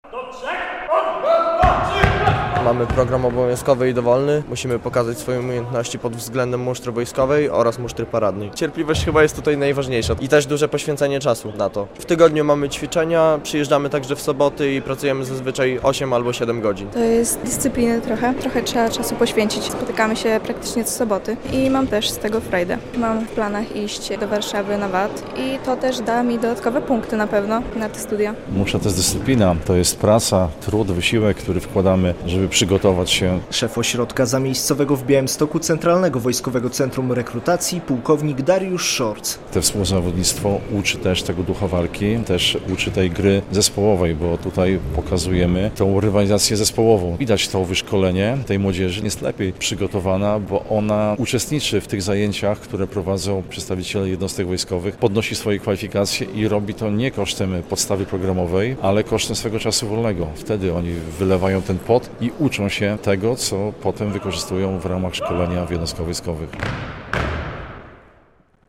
W XIV Liceum Ogólnokształcącym Białymstoku trwa X Wojewódzki Przegląd Musztry klas mundurowych i wojskowych.
X Wojewódzki Przegląd Musztry - relacja